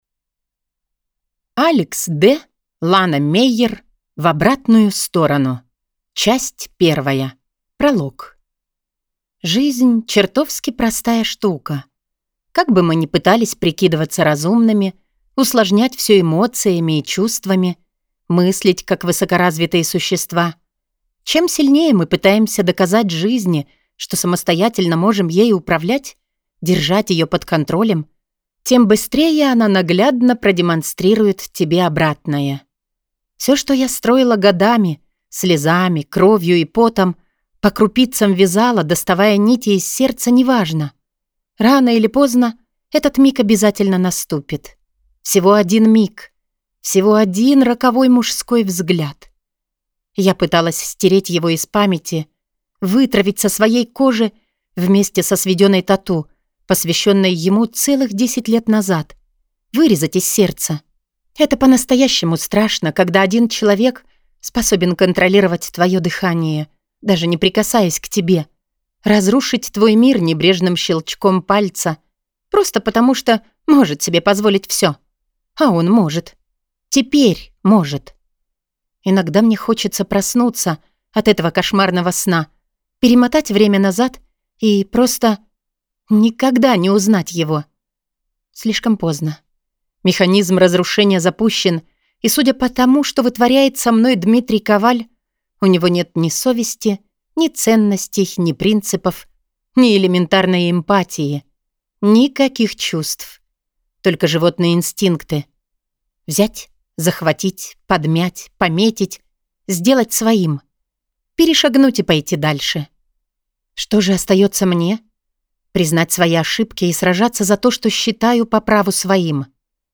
Аудиокнига В обратную сторону | Библиотека аудиокниг
Прослушать и бесплатно скачать фрагмент аудиокниги